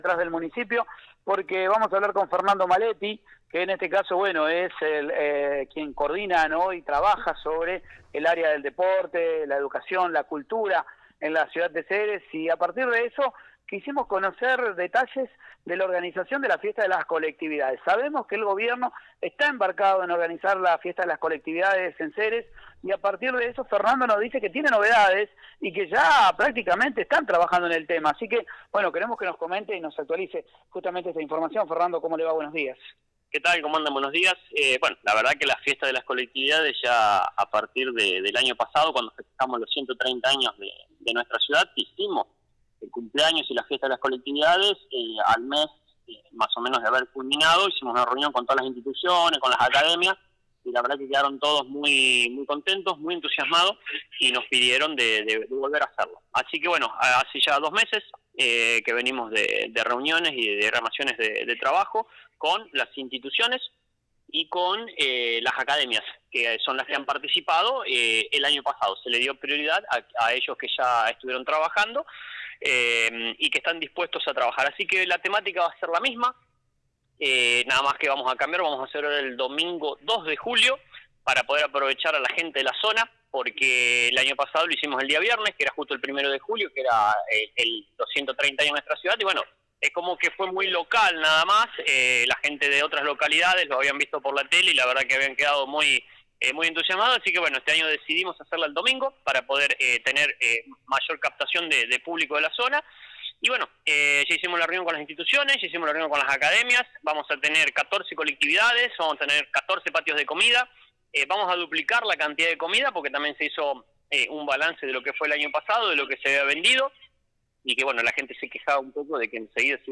Escucha la entrevista con el Sub Secretario de Educación, Cultura y Deportes.